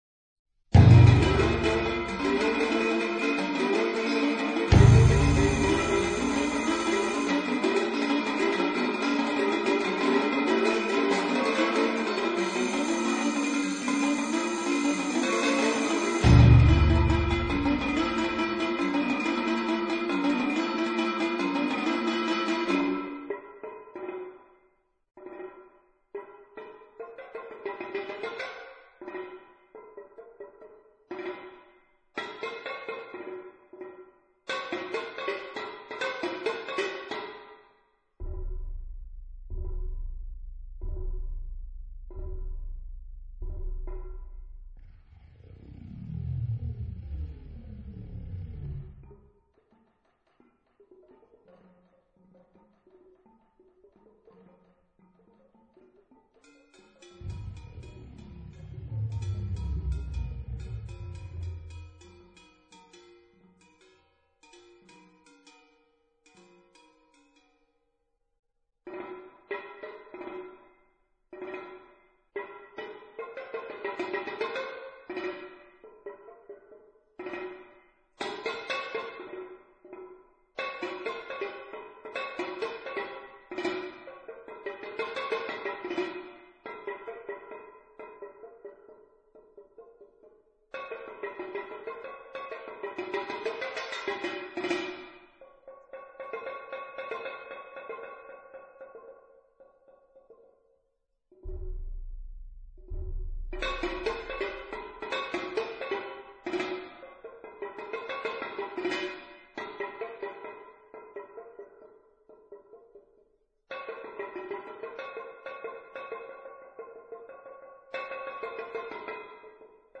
Maraton soudobé hudby 2004